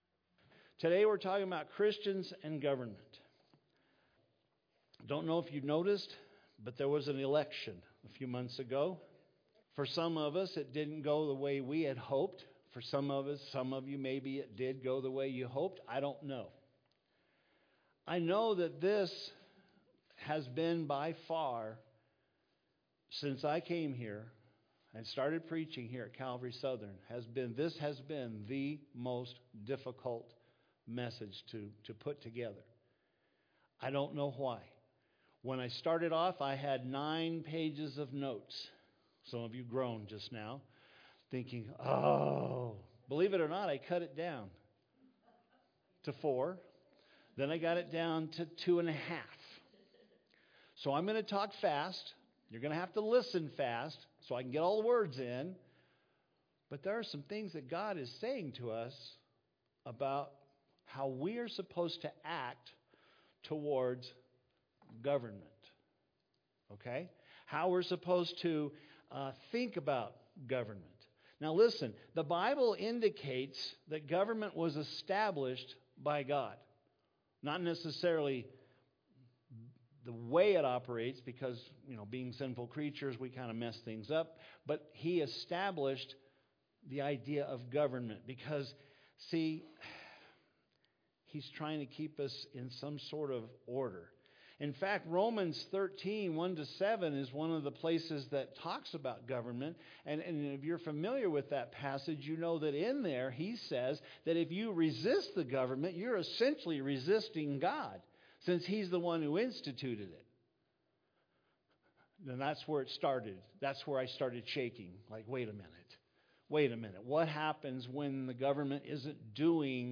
Today's sermon is an introductory study of God's Word, into the abundant life of a Christian and Government.